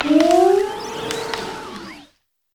Grito de Brambleghast.ogg
Grito_de_Brambleghast.ogg.mp3